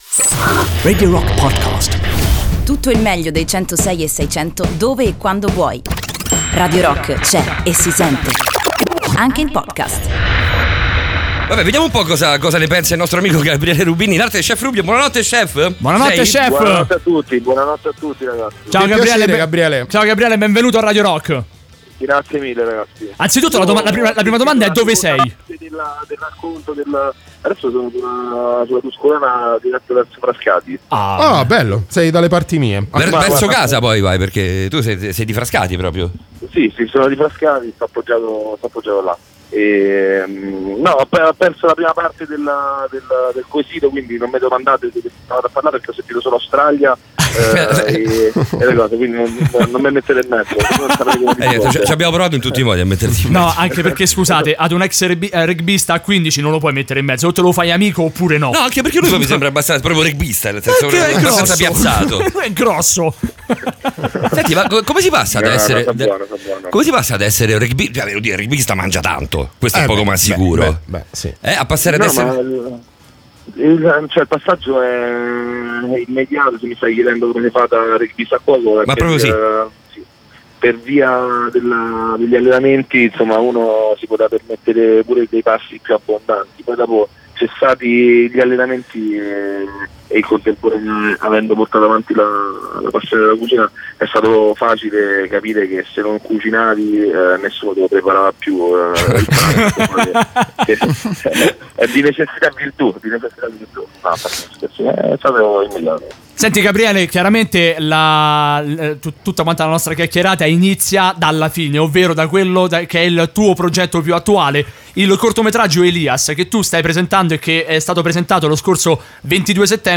Intervista: Chef Rubio (07-10-18)
Gabriele Rubini, in arte Chef Rubio, in collegamento telefonico per approfondire la campagna WWF che sta promuovendo e per presentare il film ELIAS, girato nella comunità ROM di Roma.